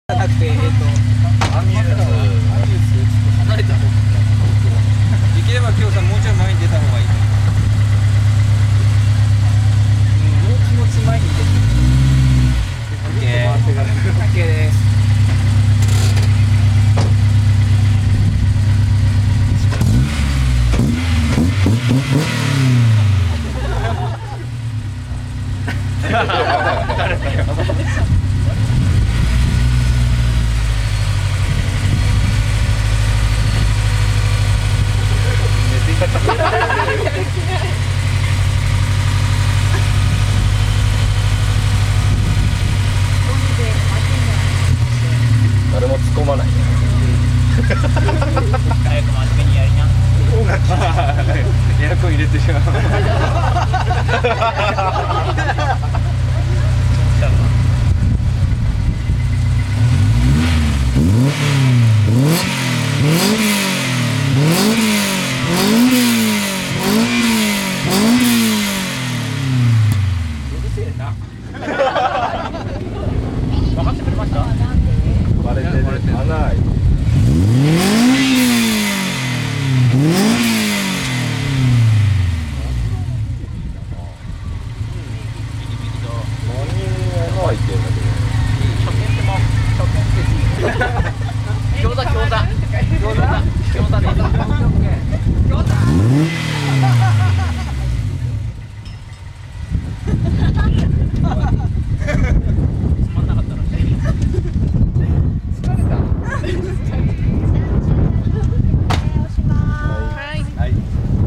(今回はS2000マフラーサウンド録音オフだよ〜）
マイク：業務用ガンマイク
『※ぴんぽ〜ん 　今回は空ぶかしメインで〜す(^◇^;)』